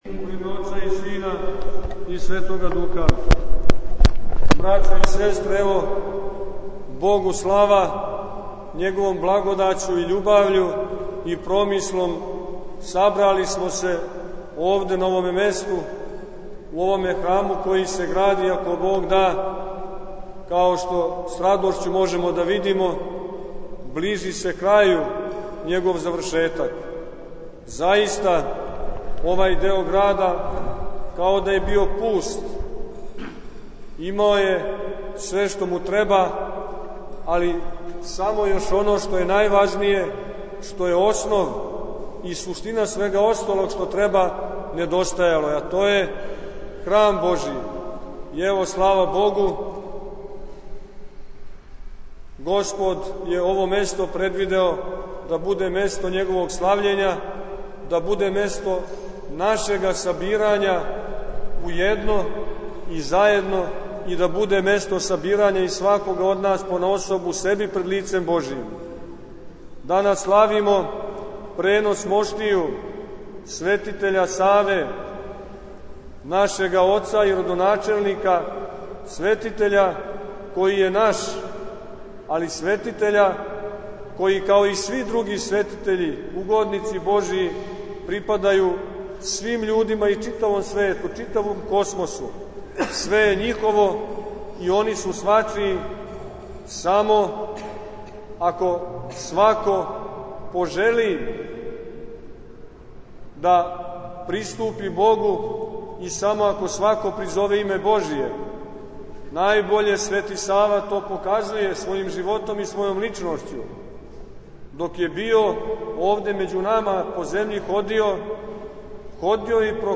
Беседа Епископа Порфирија Фотографије